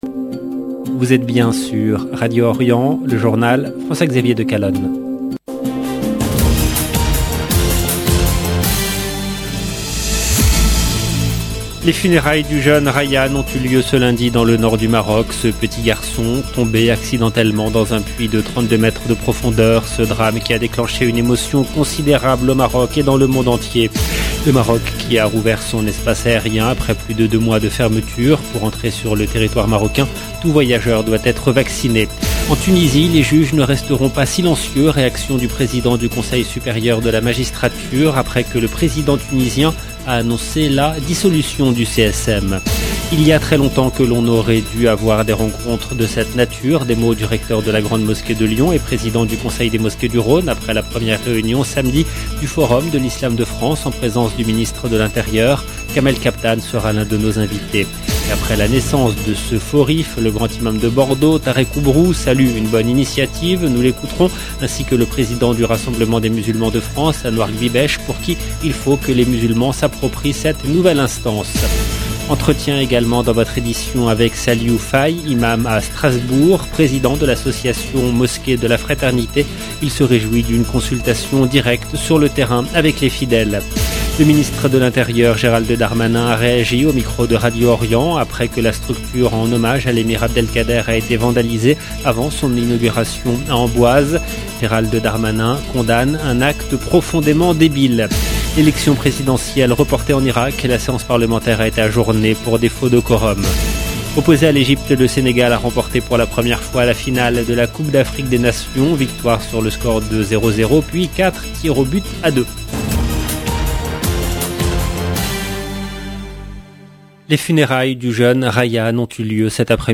Le ministre de l'Intérieur Gérald Darmanin a réagi au micro de Radio Orient après que la sculpture en hommage à l’Emir Abdelkader a été vandalisée avant son inauguration à Amboise.